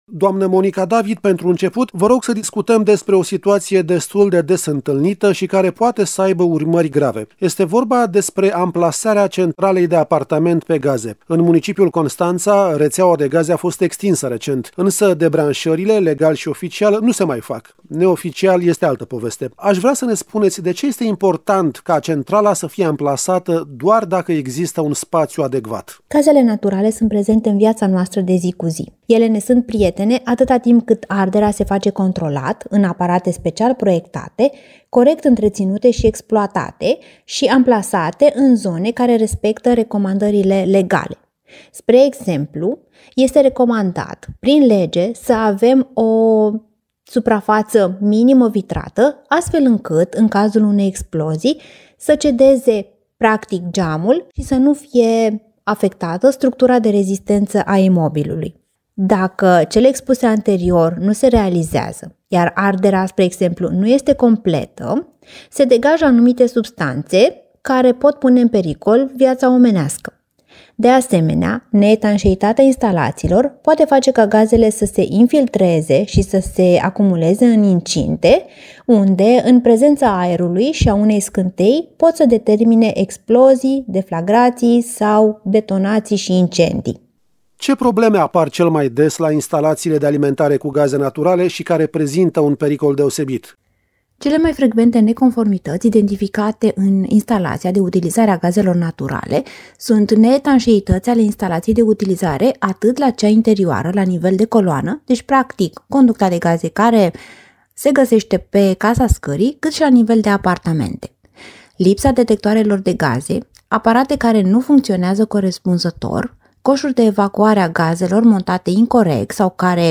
Este nevoie de rigoare și de conștientizarea pericolului, când folosim instalații alimentate cu gaze naturale, astfel încât sursa de căldură, de apă caldă și de confort din casă să nu se transforme instantaneu într-o bombă. Este concluzia interviului